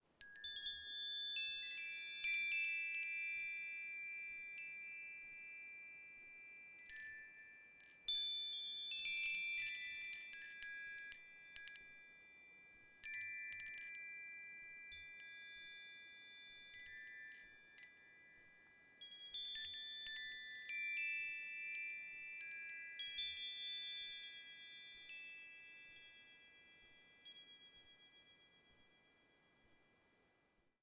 2. Set four, gentle repeating alerts spread across your waking hours (I like “Windchimes” for iPhone or download samples from Sound Snap).
metal-pretty-wind-chimes-ringing-naturally.wav